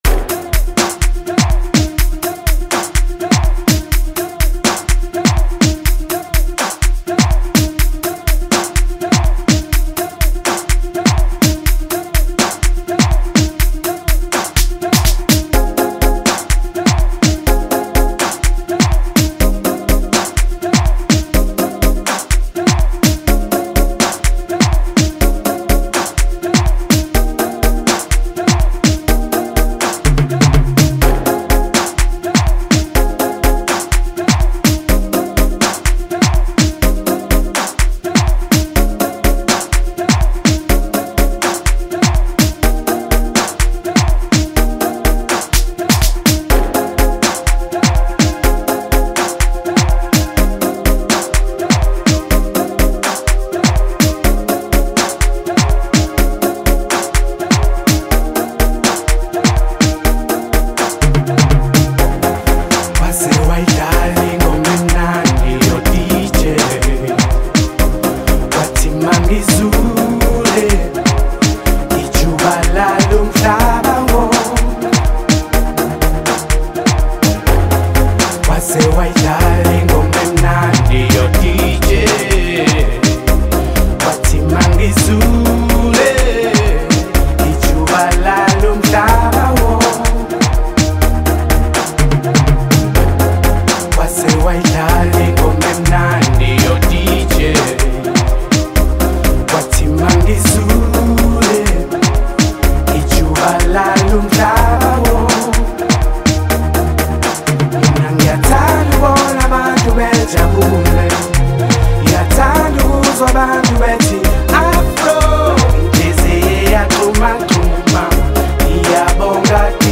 • Genre: Afrobeat